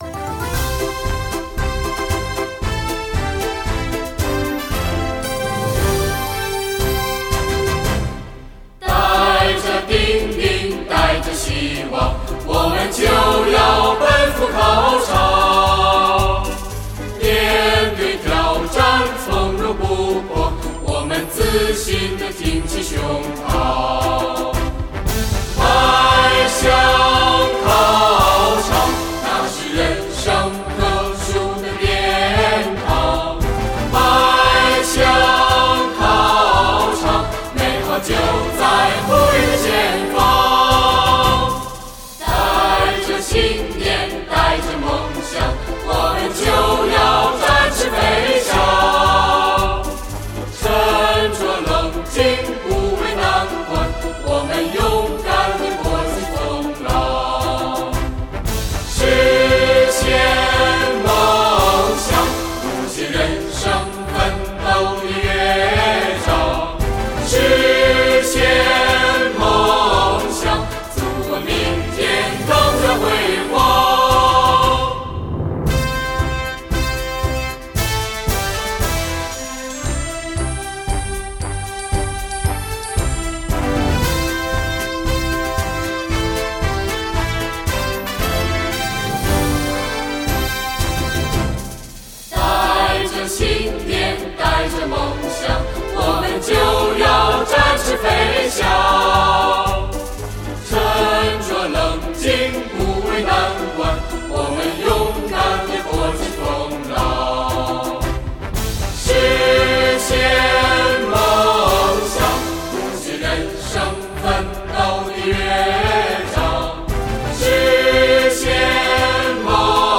乐曲为2／4拍、大调曲式，节奏分明，富有朝气。全曲结构规整，分二个乐段，第一段平稳、刚健，第二段高亢、嘹亮。
高考歌《迈向考场》伴奏下载：